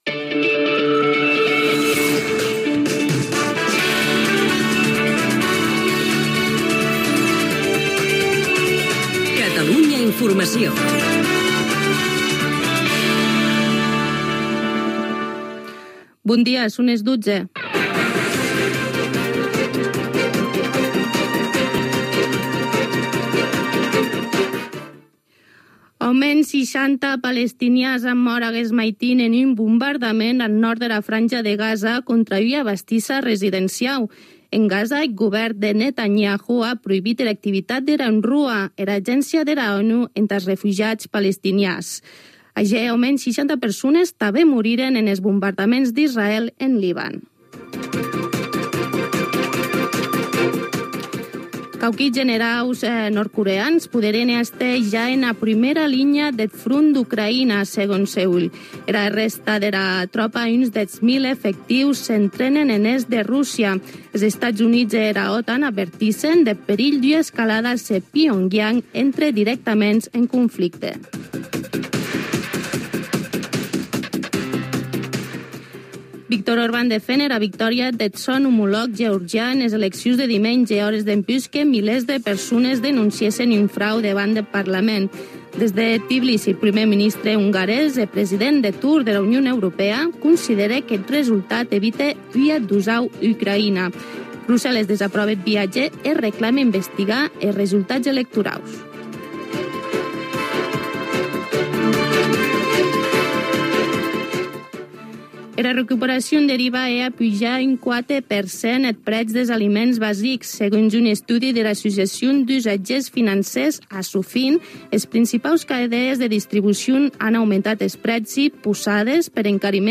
Indicatiu de l'emissora, hora, resum informatiu: Gaza, Ucraïna, Geòrgia, IVA, pluges torrencials al País Valencià, etc. El temps, indicatiu. Bombardaments a Gaza, informació i crònica
Informatiu